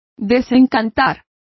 Complete with pronunciation of the translation of disillusion.